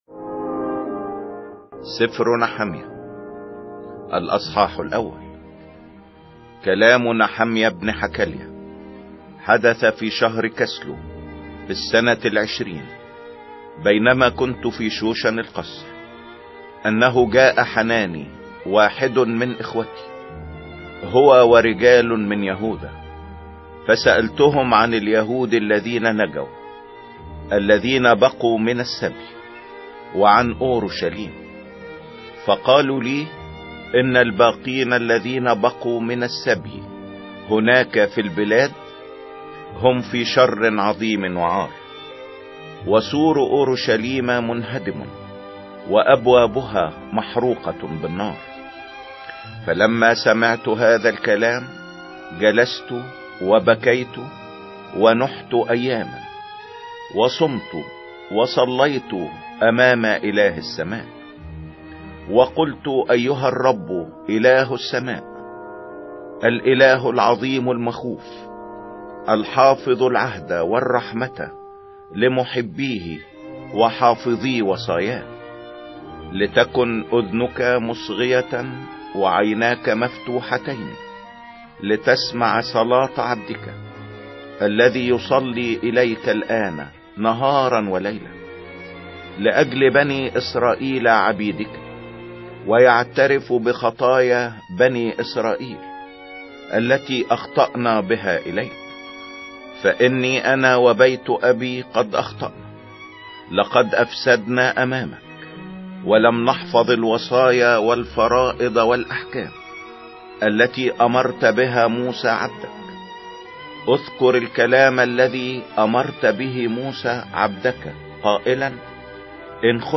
سفر نحميا 01 مسموع